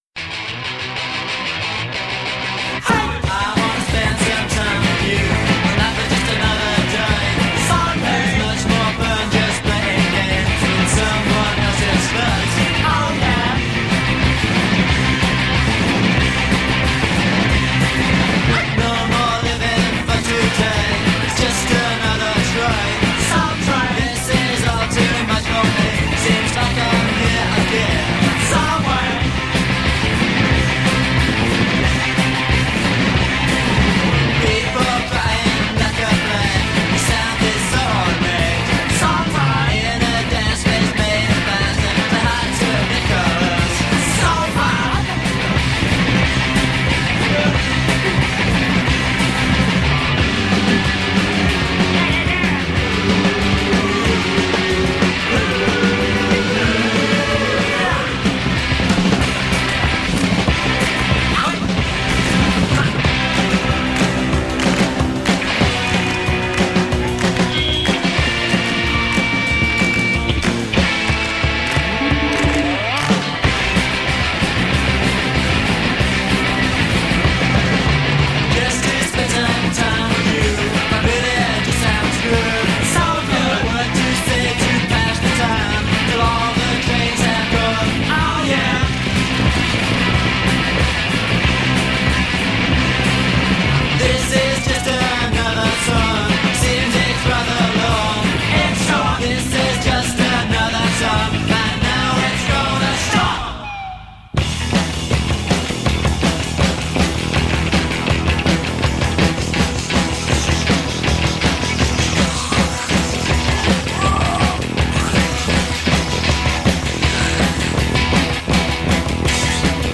The Dawn Of Post-Punk.